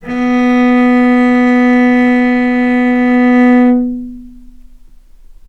healing-soundscapes/Sound Banks/HSS_OP_Pack/Strings/cello/ord/vc-B3-mf.AIF at 01ef1558cb71fd5ac0c09b723e26d76a8e1b755c
vc-B3-mf.AIF